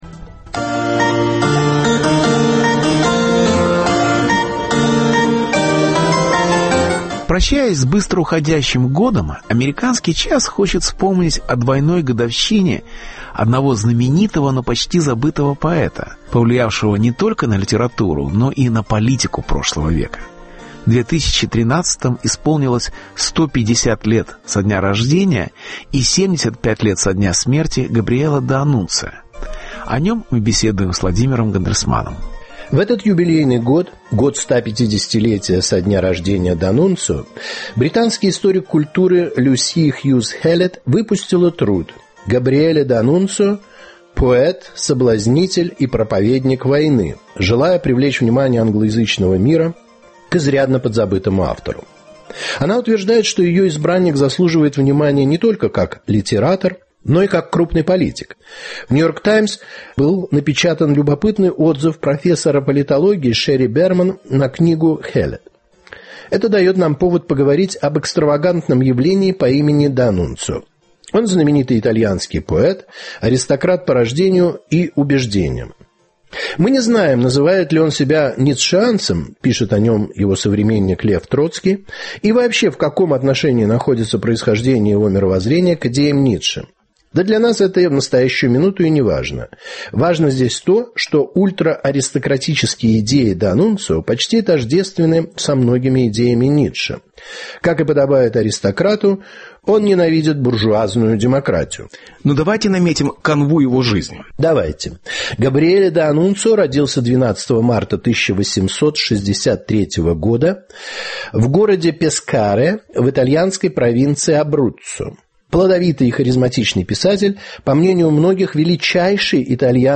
Юбилей Габриэле д'Аннунцио: поэт как симптом. Беседа с Владимиром Гандельсманом